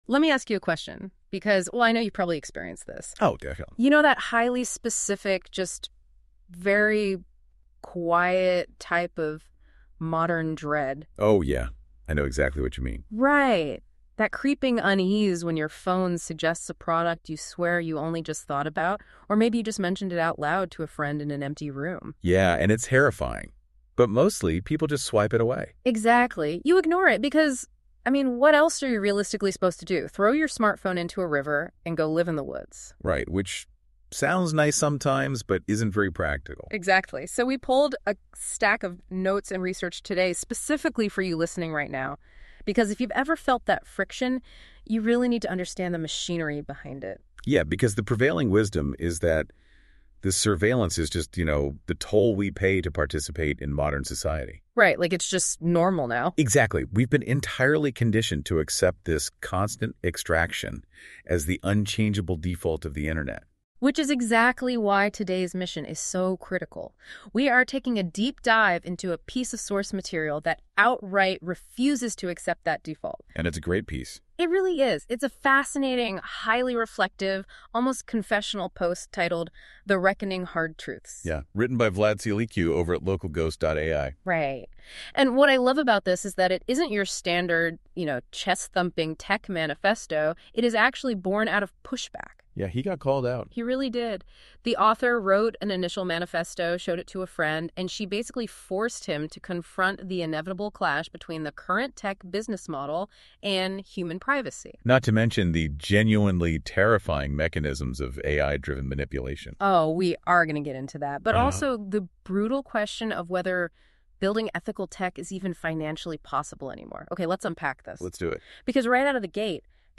> EPISODE 02 // OFFLINE-READY NOTEBOOKLM AUDIO ▶ ❚❚ 00:00 / --:-- DOWNLOAD A good friend read the manifesto and we ended up talking for hours.